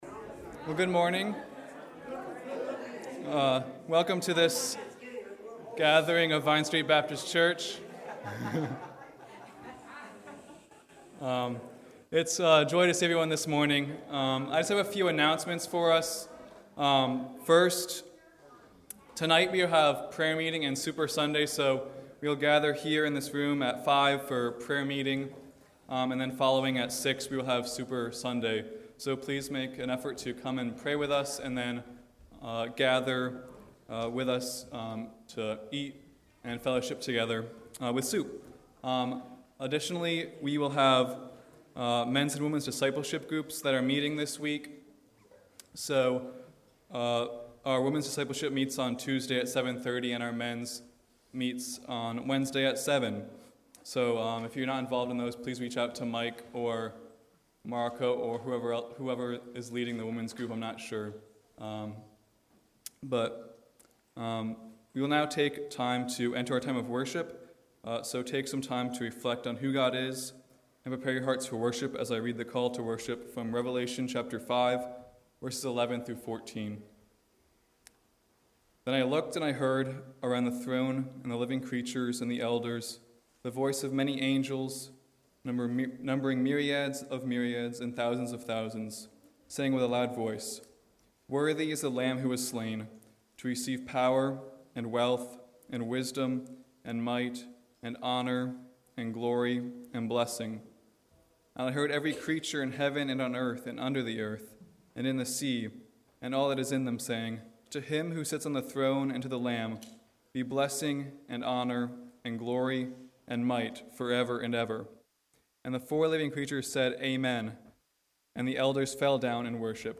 March 06 Worship Audio – Full Service
Service Type: Morning Worship